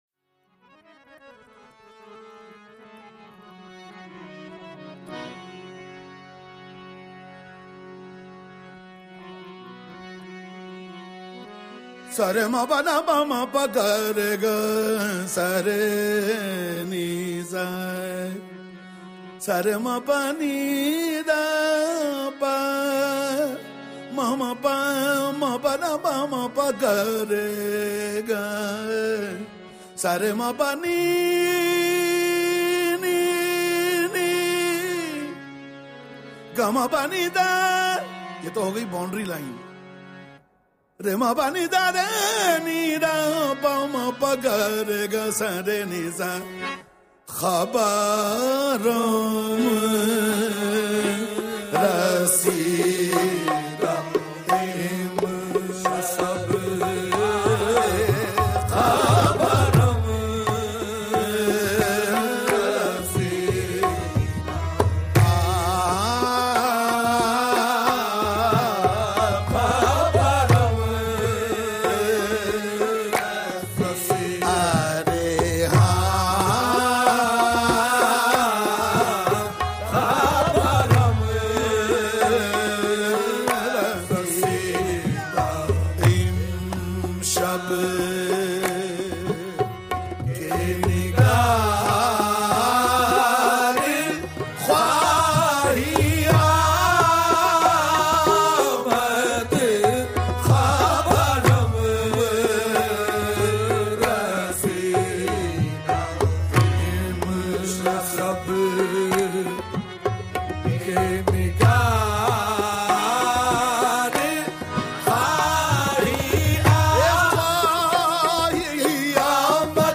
Sufi Music